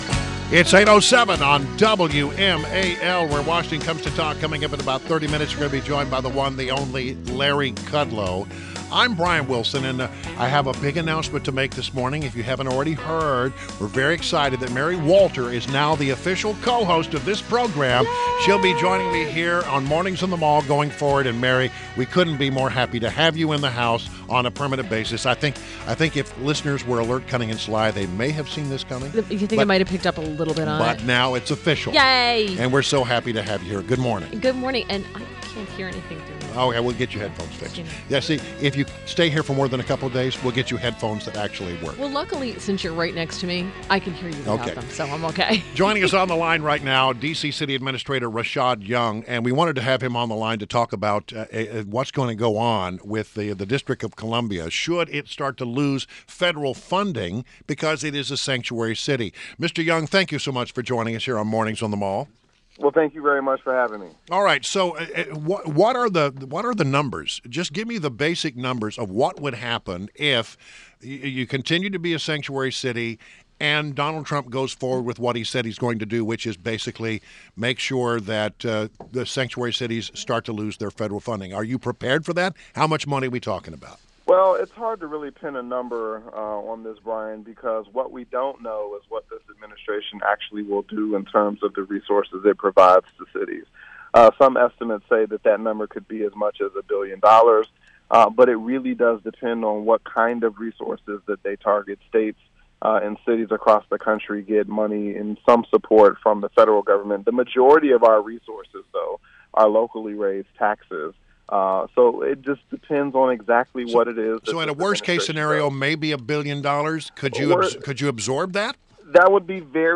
INTERVIEW – D.C. City Administrator RASHAD YOUNG